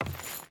Wood Chain Walk 1.ogg